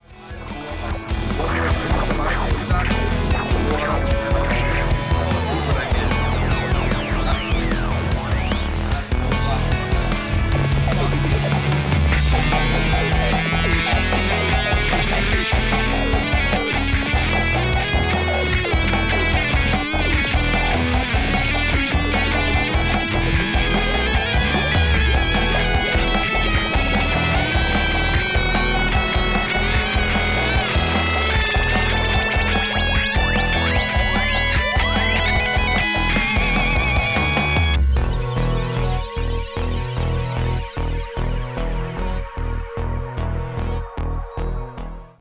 Japanese New Wave / Rock Pop